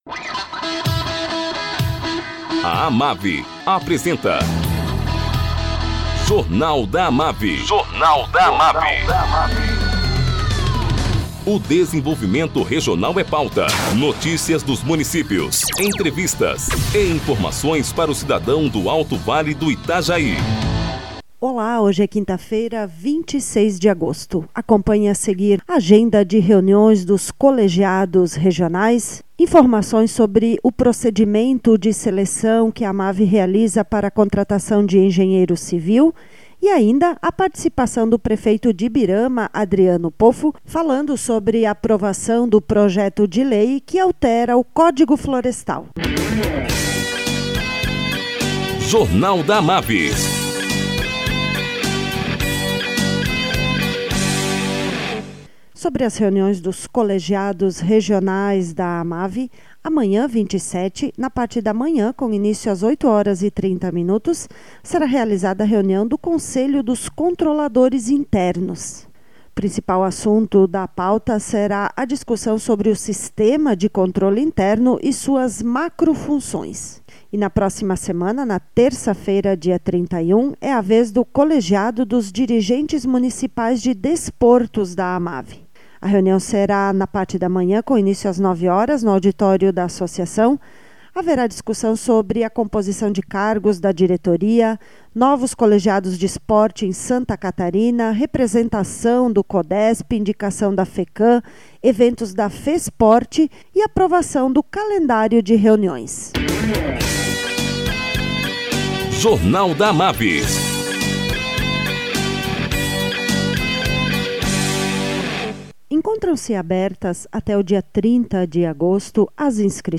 Prefeito de Ibirama, Adriano Poffo, fala sobre a aprovação do PL 2510/19 que atribui competência aos municípios para definir sobre as áreas de preservação permanente (APPs) em áreas urbanas.